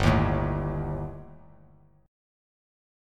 Gsus2#5 chord